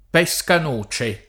pescanoce [ p HS kan 1© e ]